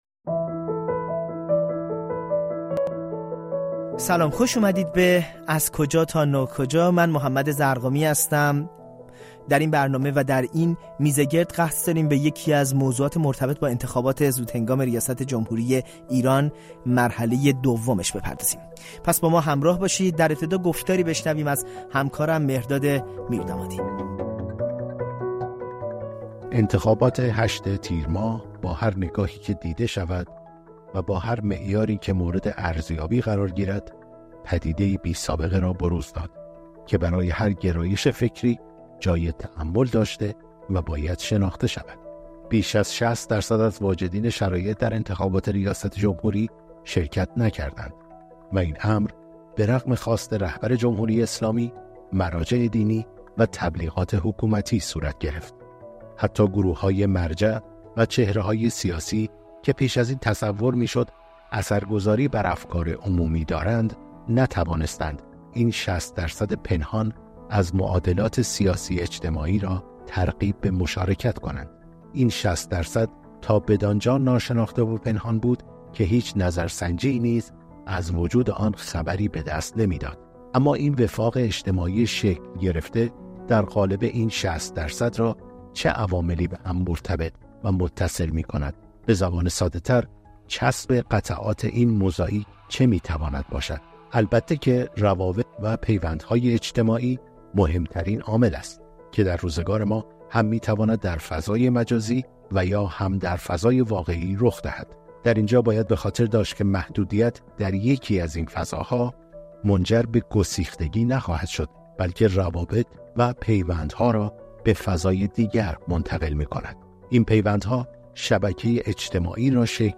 میزگرد رادیویی: چطور می‌توان ۶۰ درصدی را که به انتخابات «نه» گفتند، فهمید؟